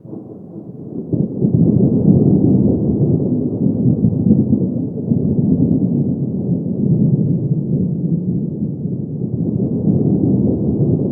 THUNDER 3 -R.wav